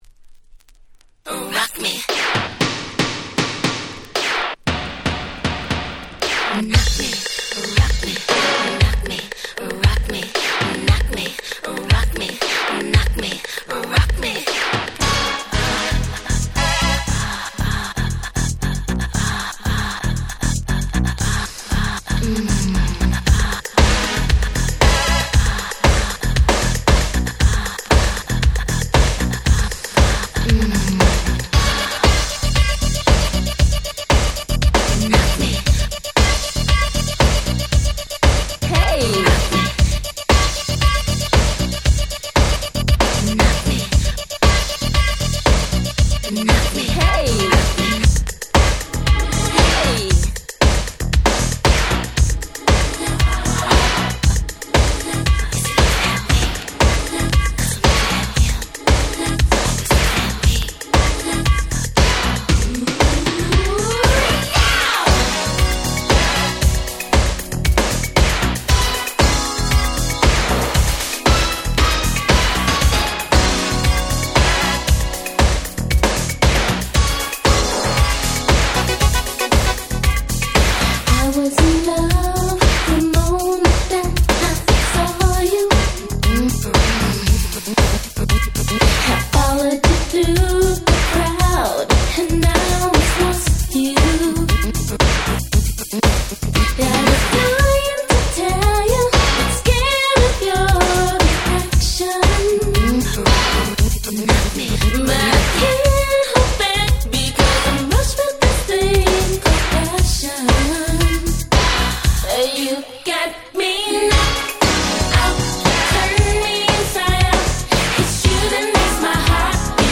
88' Smash Hit R&B !!
New Jack Swing前夜的な跳ねたBeatにエモーショナルなVocalが堪らない88年のヒット作。